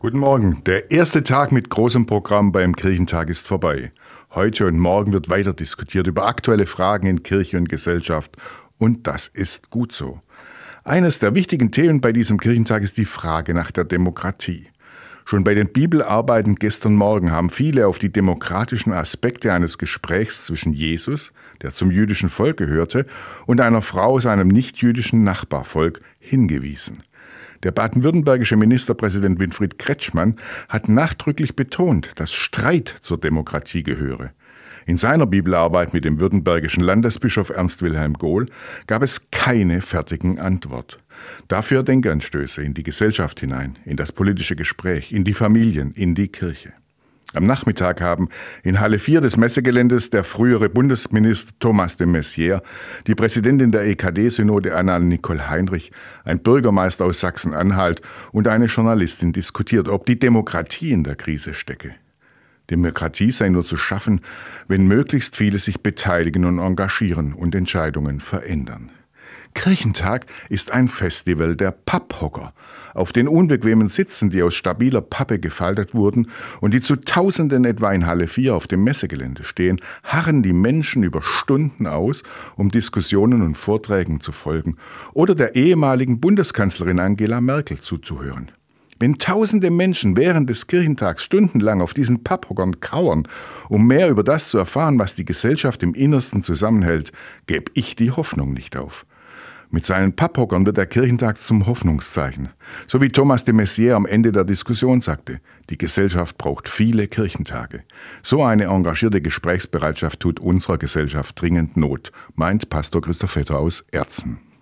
Weserbergland: Radioandacht vom 02. Mai 2025